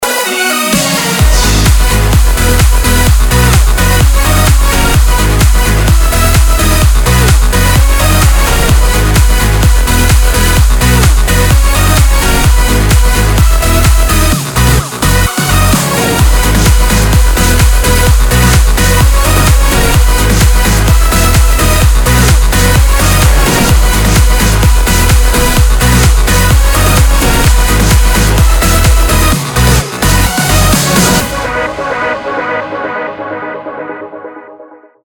• Качество: 320, Stereo
в стиле Progressive house